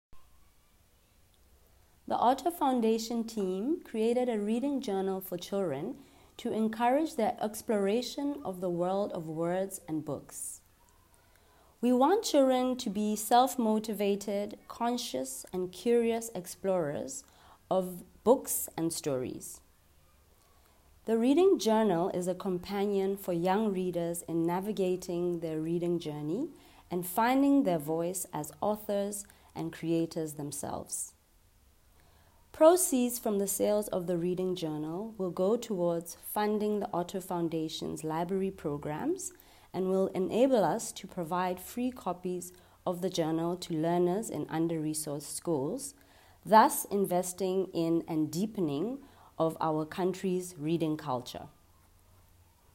The launch was held at the Sunflower Learning Centre - one of the libraries established and managed by the Otto Foundation in District Six, Cape Town.